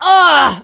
rosa_death_04.ogg